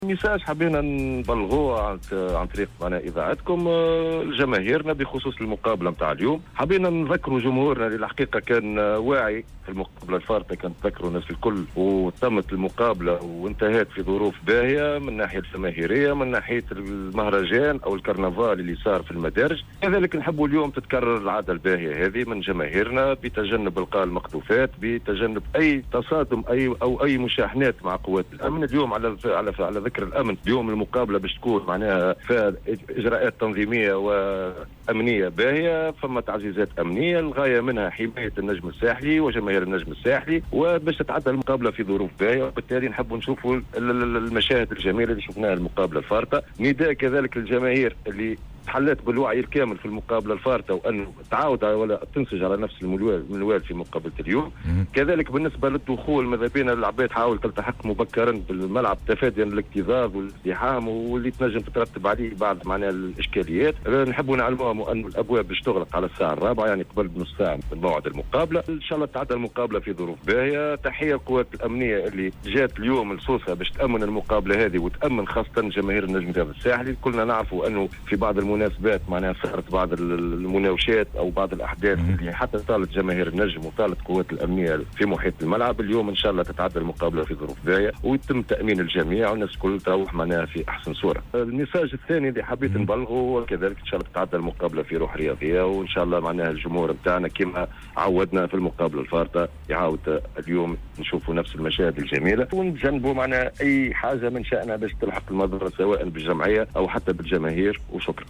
في تدخل له عبر موجات جوهرة أف آم